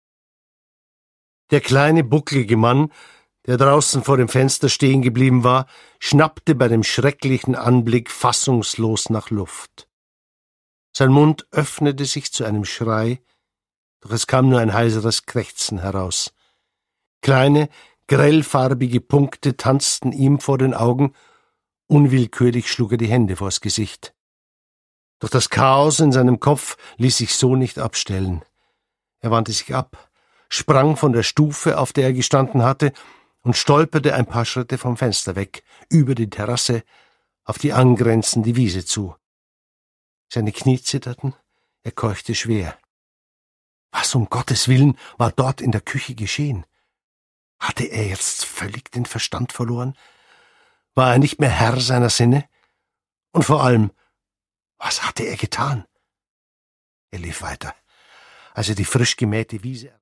Produkttyp: Hörbuch-Download
Gelesen von: Jörg Maurer